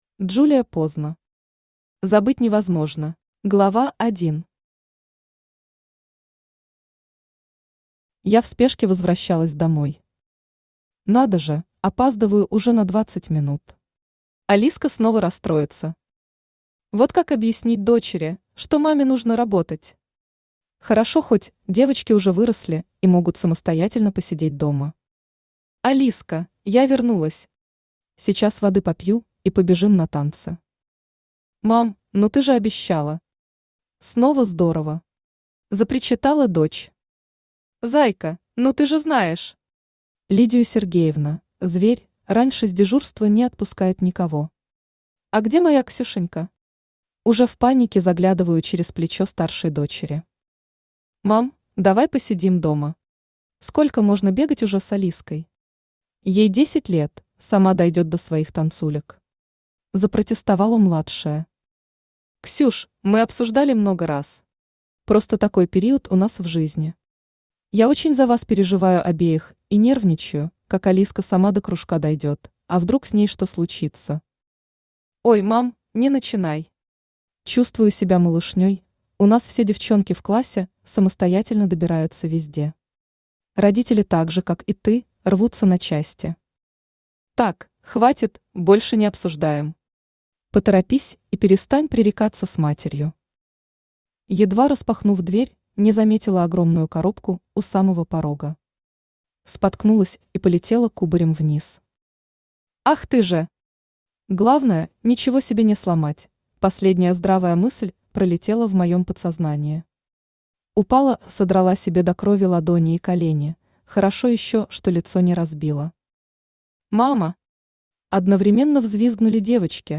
Аудиокнига Забыть невозможно | Библиотека аудиокниг
Aудиокнига Забыть невозможно Автор Джулия Поздно Читает аудиокнигу Авточтец ЛитРес.